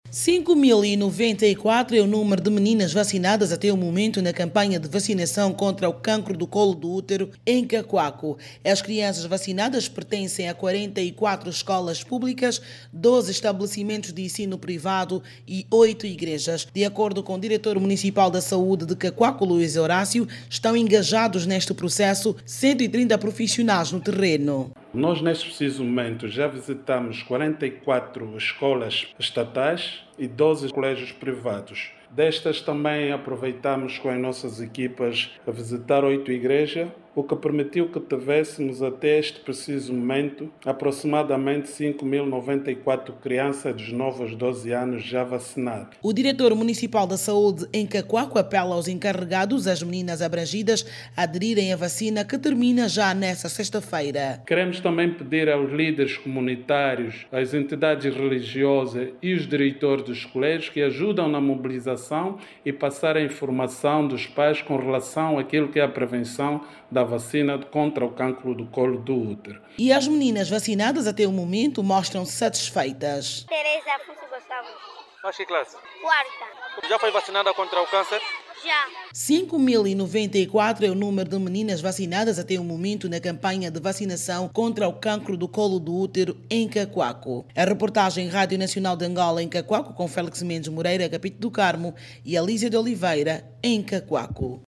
Jornalista
RADIO-CACUACO-VACINA-12HRS.mp3